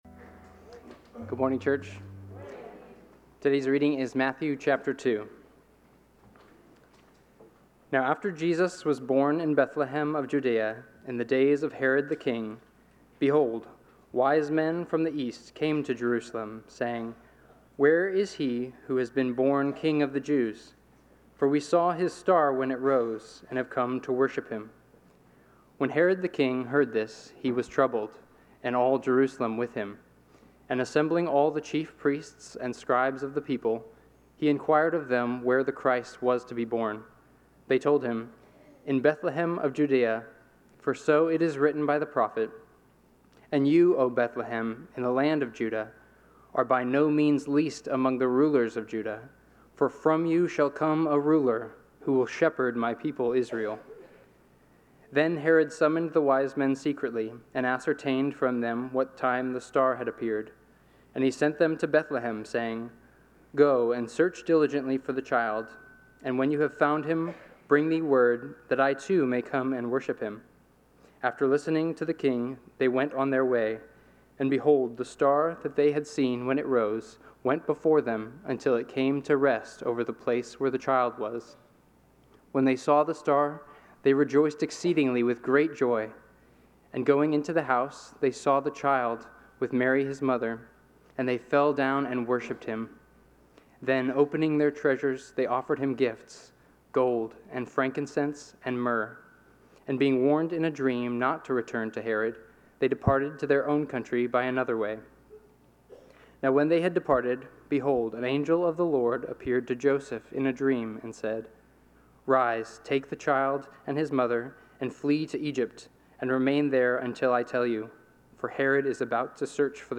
Sermon Notes: The King's Reception